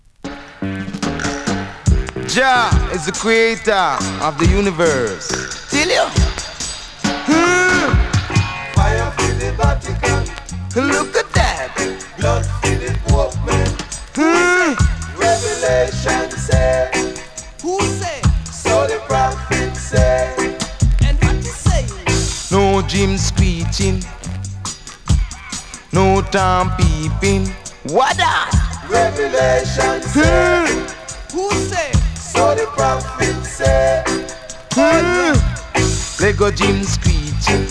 7"/Vintage-Reggae, Roots